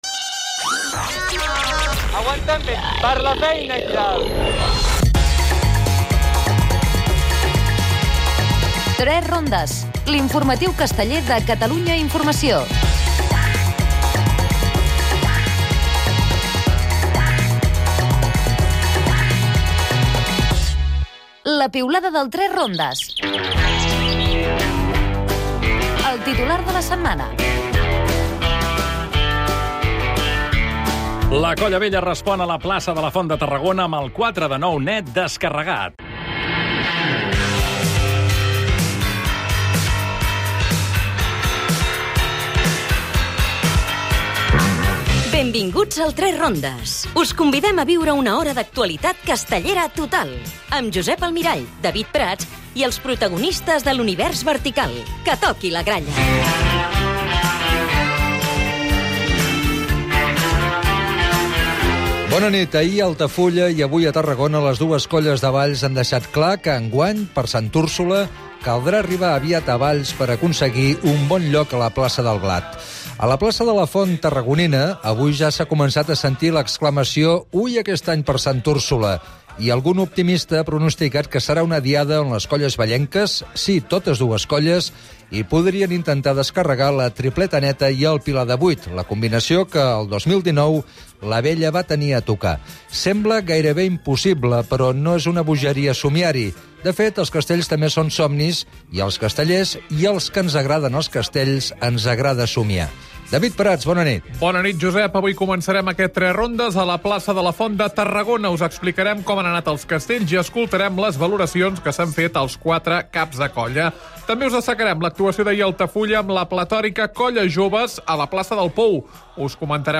A "3 rondes" hem estat a les diades del primer diumenge de Festes de Santa Tecla de Tarragona, Valldoreix i Altafulla. Entrevista